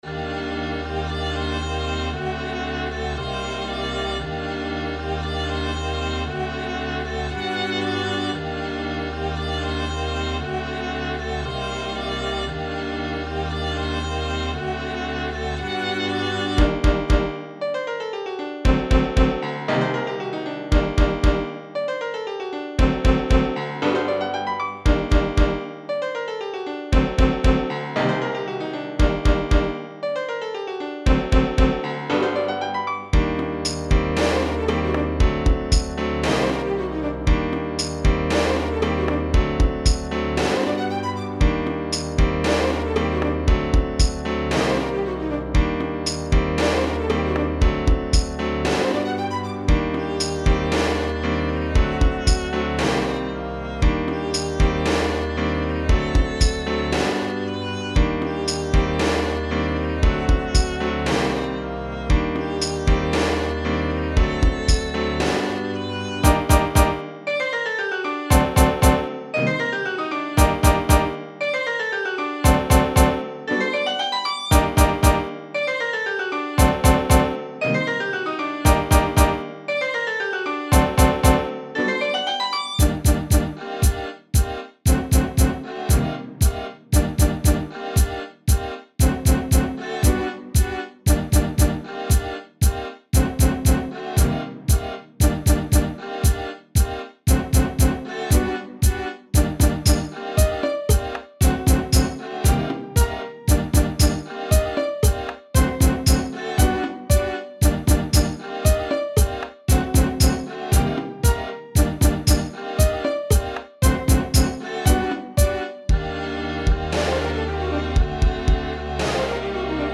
tried to make it a lil mischievous